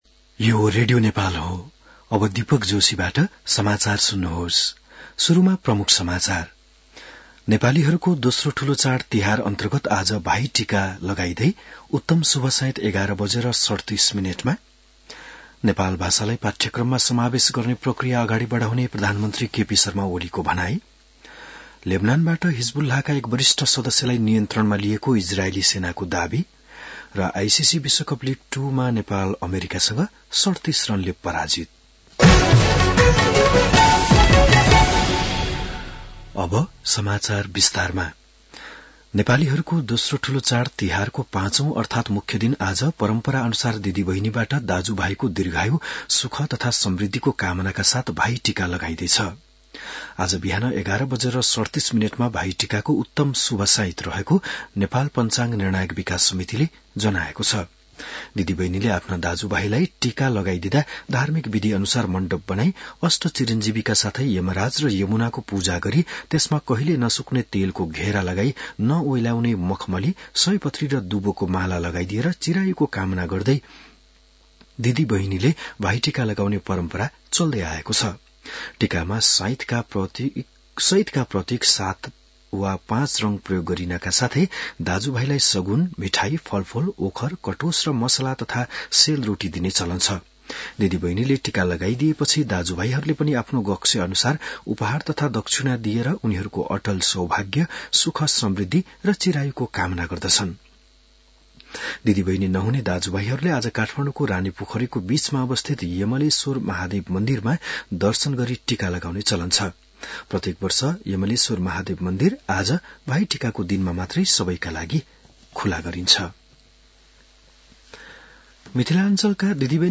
बिहान ९ बजेको नेपाली समाचार : १९ कार्तिक , २०८१